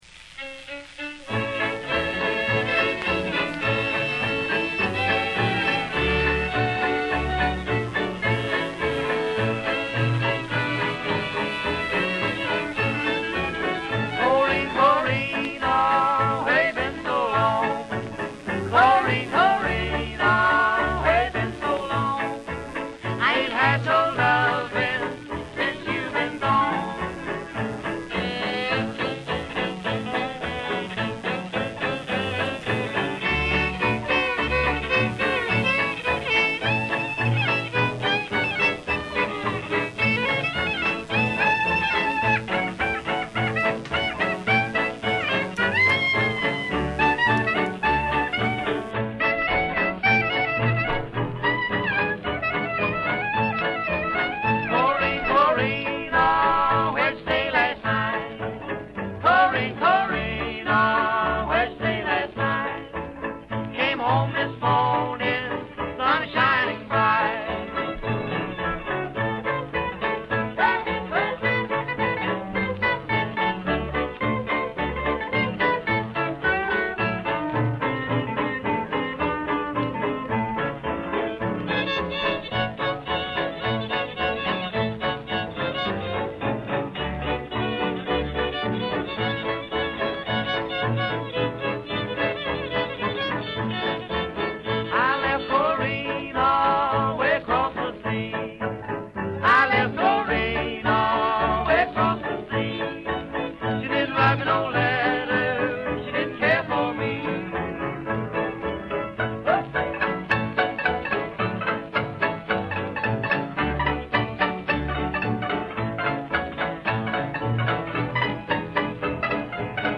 September 28, 1935 in Dallas, Texas